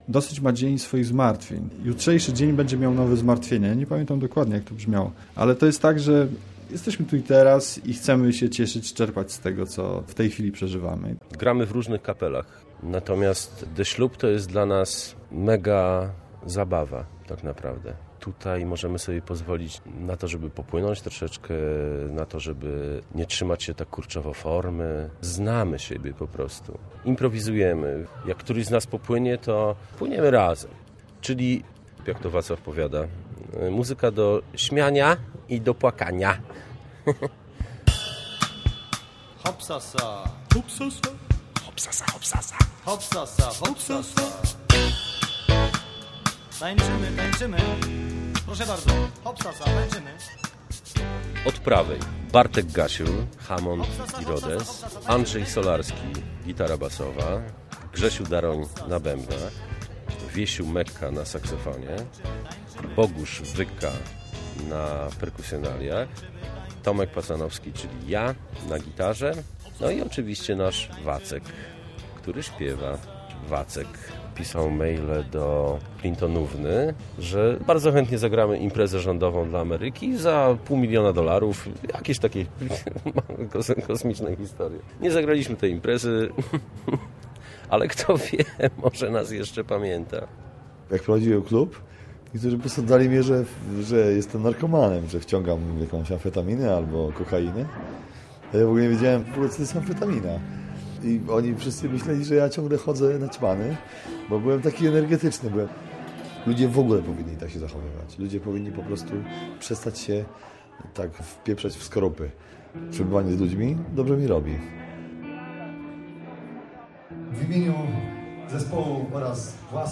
The Ślub - reportaż
Pełne, profesjonalne brzmienie, zabawne i mądre teksty. Jak na zespół The Ślub zareagowała poznańska publiczność?